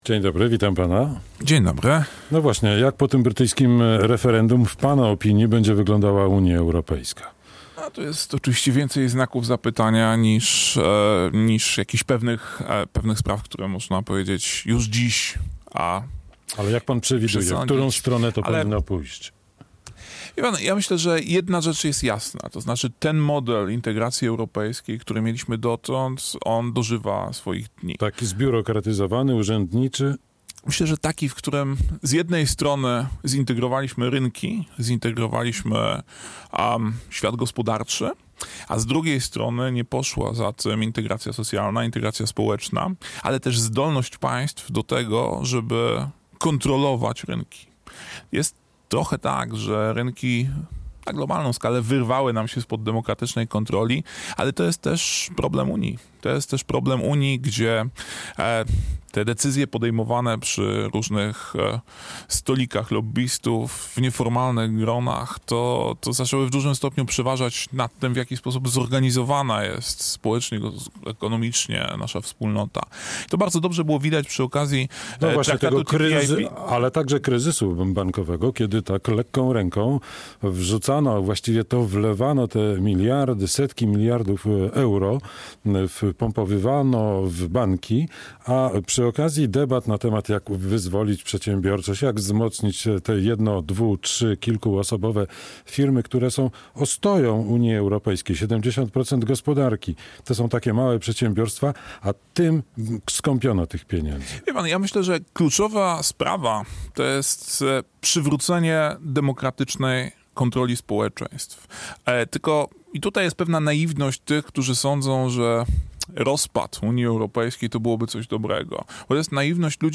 Radio Białystok | Gość | Adrian Zandberg - z Partii Razem